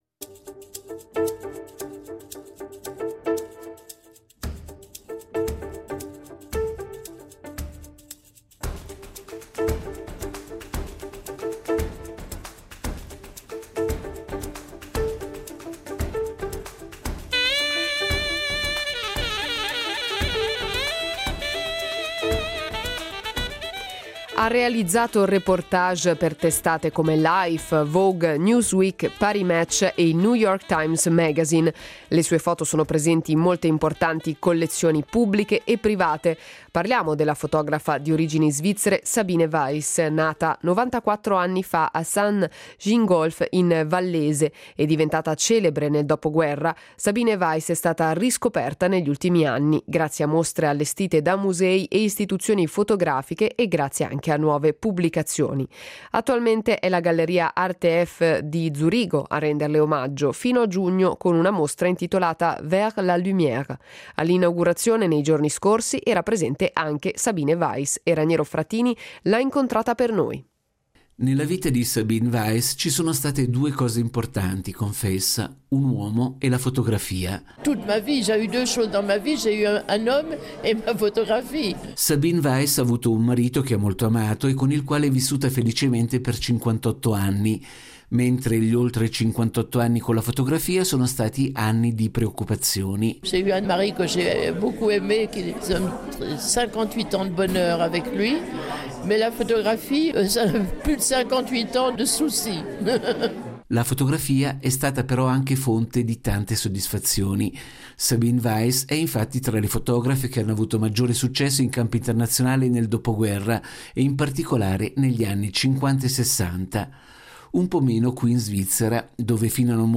Incontro con Sabine Weiss